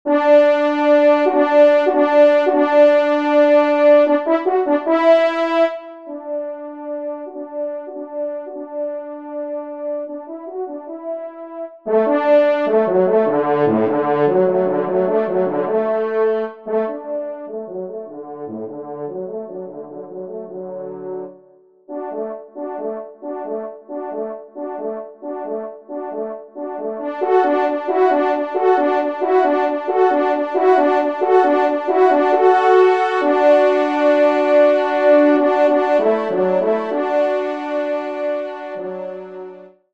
Genre :  Divertissement pour Trompes ou Cors en Ré
2e Trompe